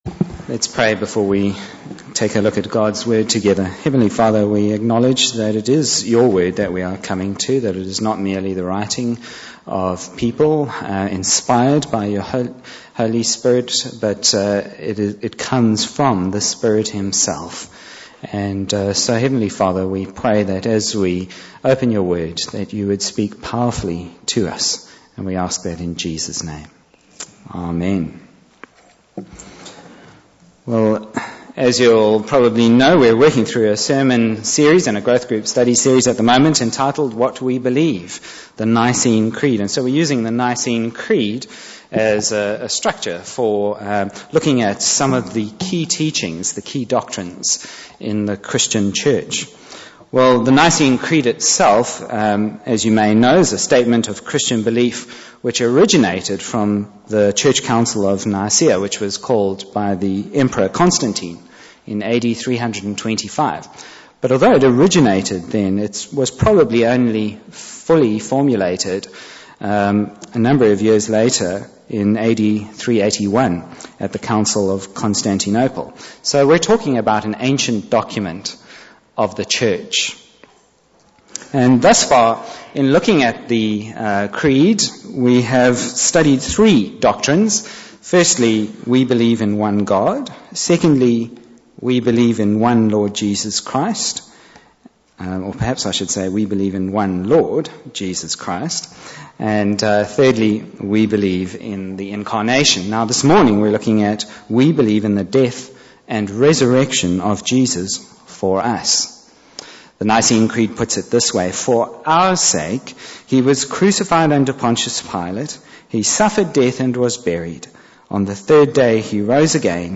Bible Text: Mark 16:1-8 | Preacher